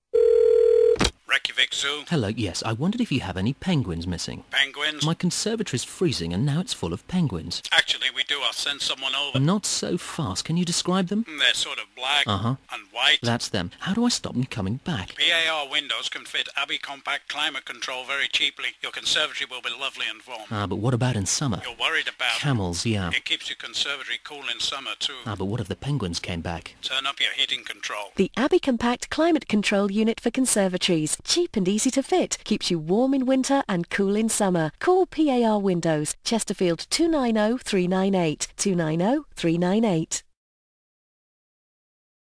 Commercial VO character
Award-winning radio commercial performance